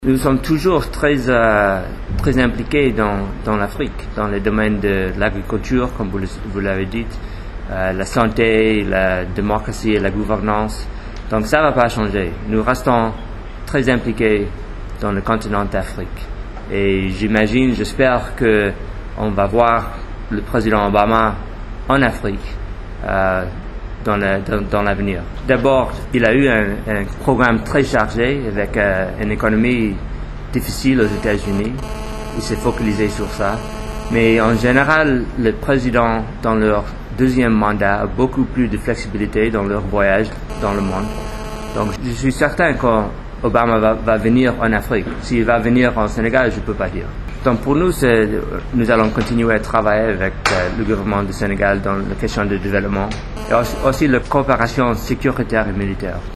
Point de presse de S.E M. Lewis Lukens.mp3 (880.82 Ko)
Pour lui, rien ne va changer mais il estime que son président va accorder beaucoup plus de temps dans son agenda pour l’Afrique. M. Lukens a fait face à la presse nationale à 06 heures du matin (heure locale) à sa résidence.